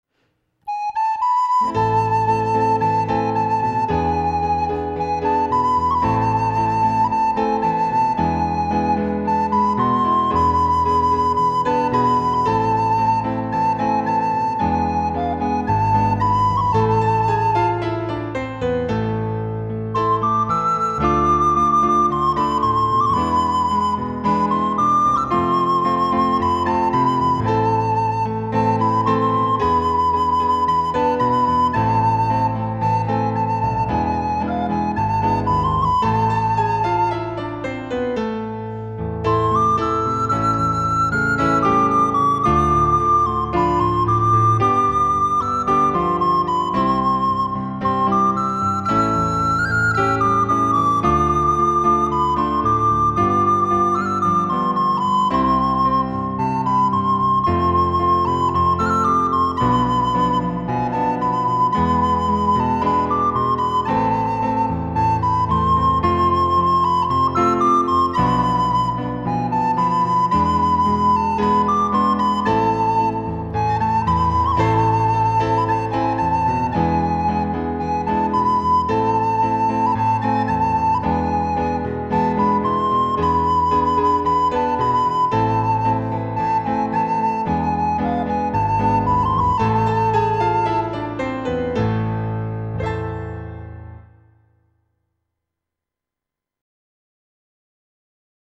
فایل صوتی MP3 اجرای مرجع
• اجرای تمیز با تمپوی استاندارد
ایرانی